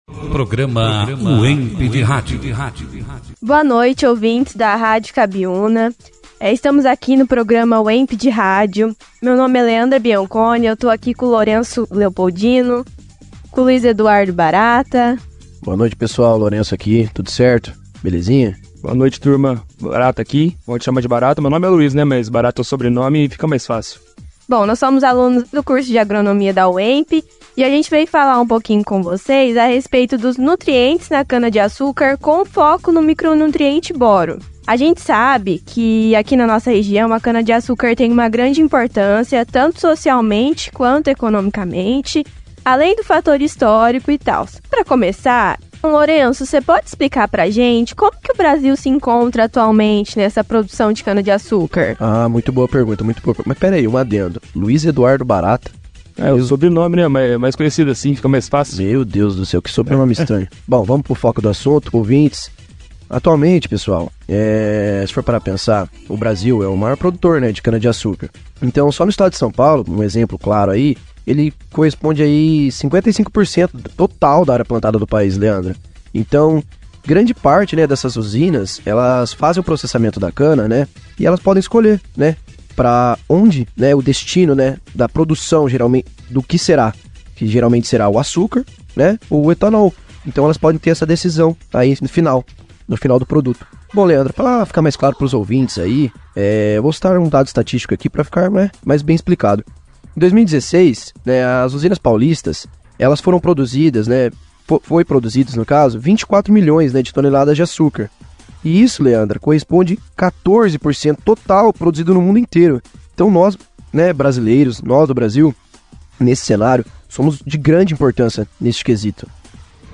Produzido e apresentado pelos alunos, Acadêmicos do 4º ano do curso de Agronomia